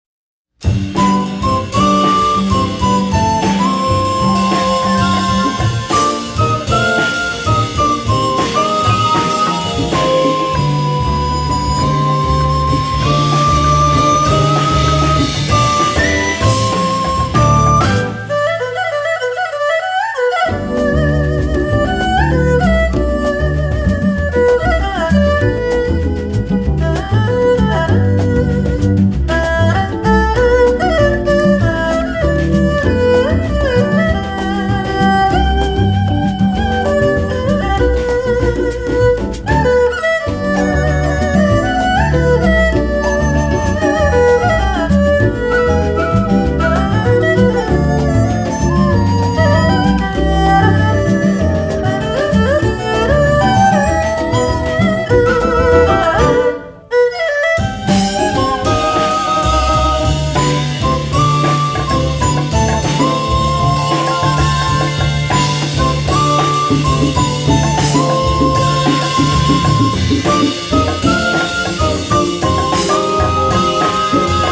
發燒演奏、發燒天碟
以演奏中國音樂混合世界音樂讓人耳目一新